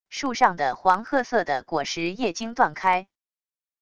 树上的黄褐色的果实叶茎断开wav音频